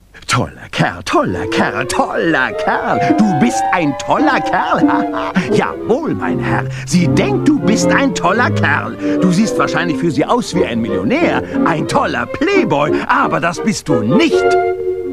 Off-Sprecher